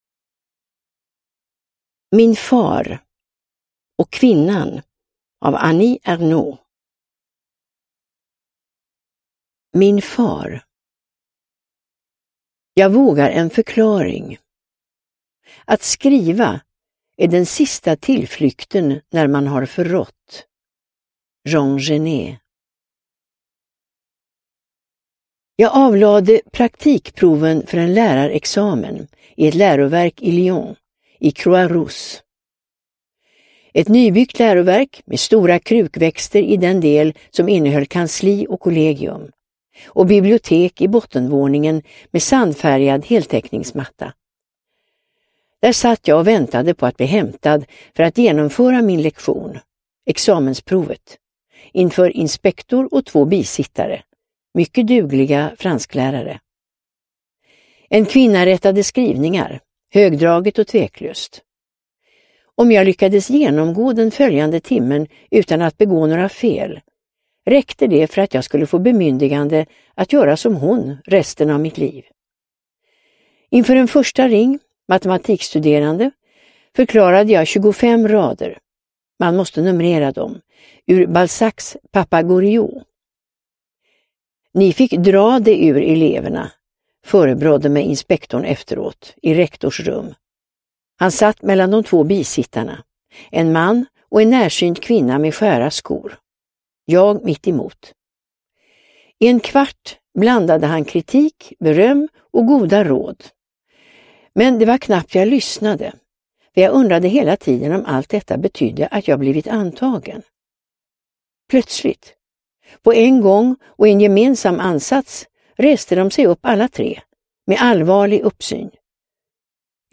Min far & Kvinnan – Ljudbok – Laddas ner